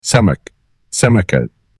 fish-in-arabic.mp3